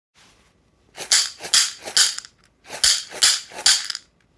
ひょうたん底 バスケットマラカス　アフリカ 民族楽器 （p505-31）
ブルキナファソで作られたバスケット素材の素朴なマラカスです。
水草とひょうたんと木の実でできています。
やさしいナチュラルな乾いた音を出します。
この楽器のサンプル音